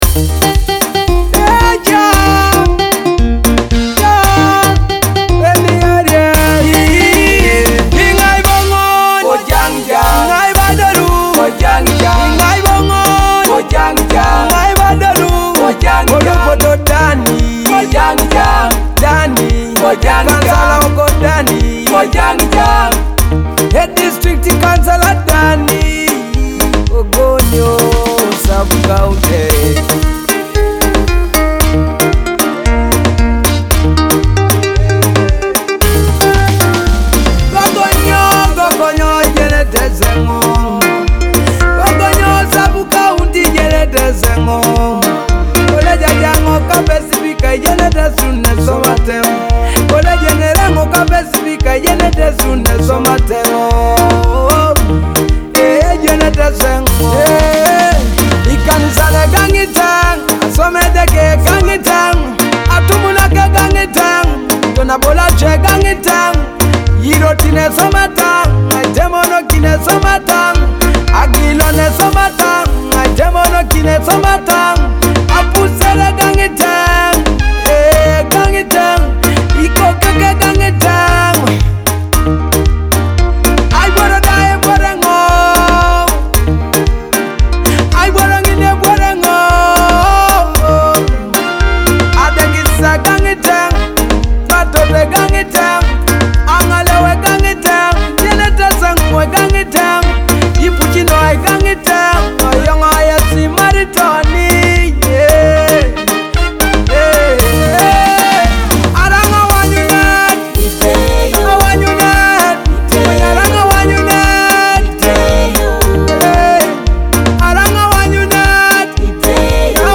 a compelling Teso political song from Palisa District.